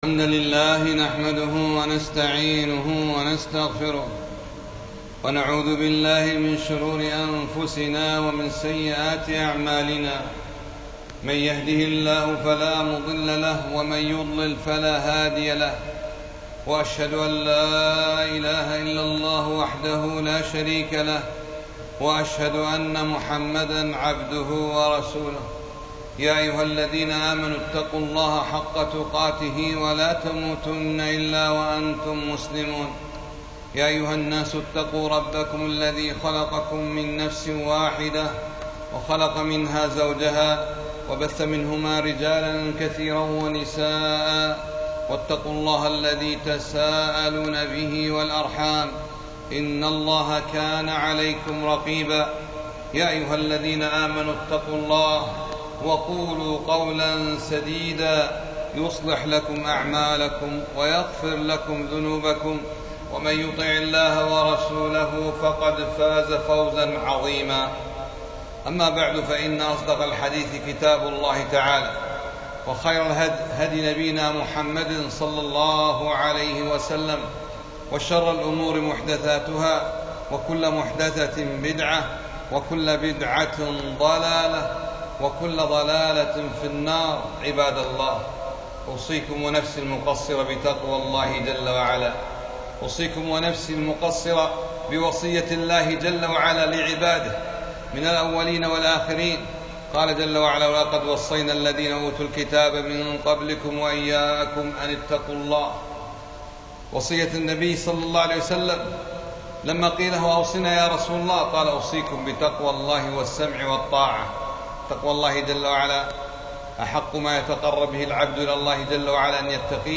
خطبة - مشروعية الشفاعة الحسنة لقضاء حوائج المسلمين